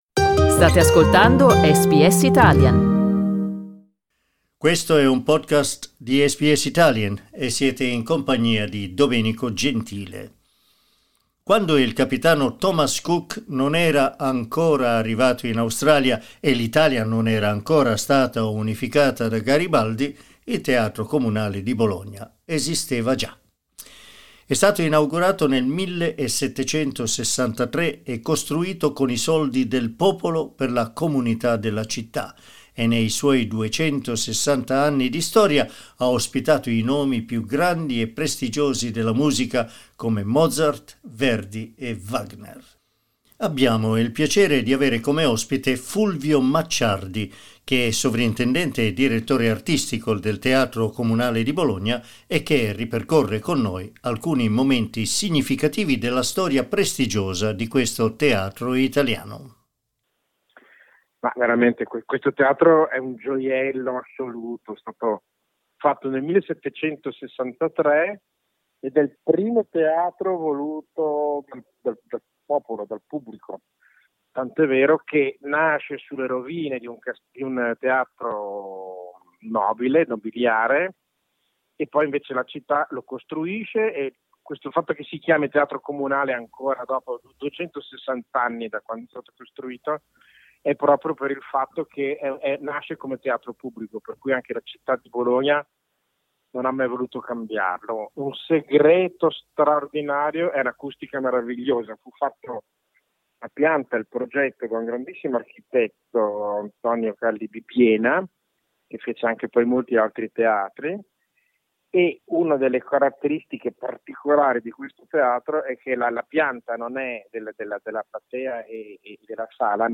Ascolta l'intervista: LISTEN TO La lunga e leggendaria storia del Teatro Comunale di Bologna SBS Italian 24:38 Italian Le persone in Australia devono stare ad almeno 1,5 metri di distanza dagli altri.